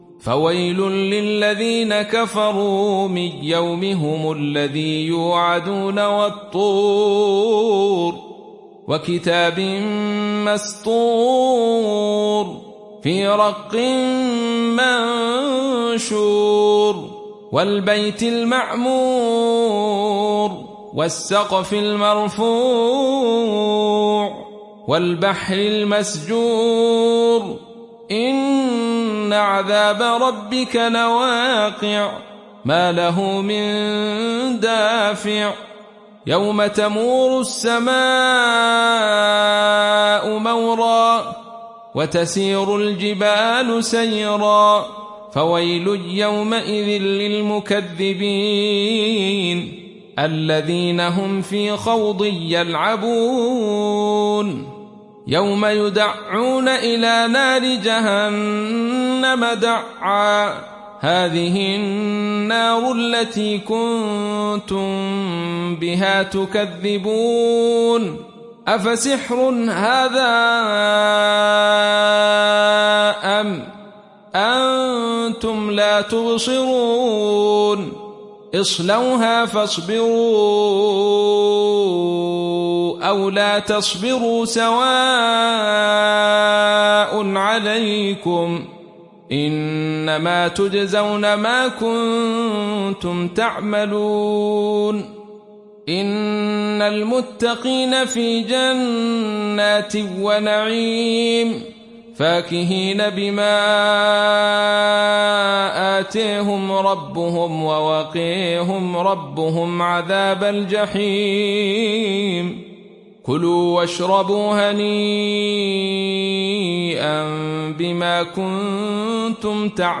Khalaf from Hamza